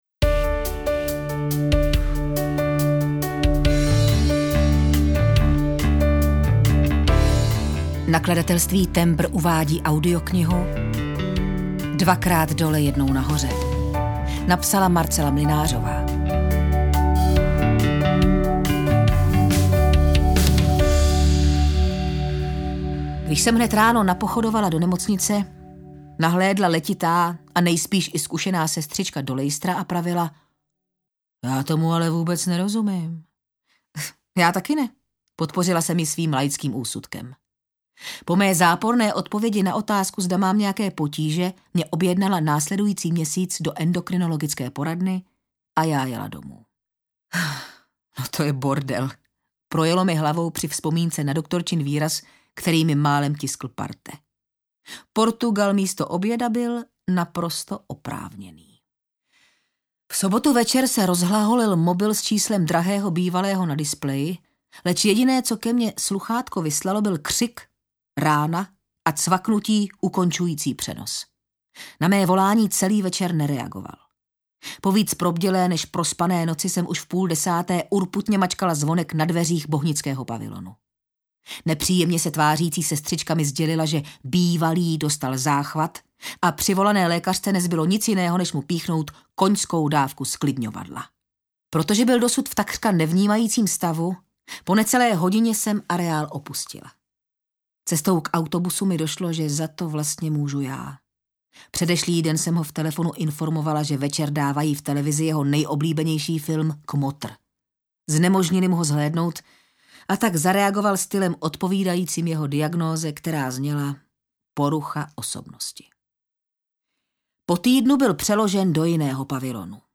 Dvakrát dole, jednou nahoře audiokniha
Ukázka z knihy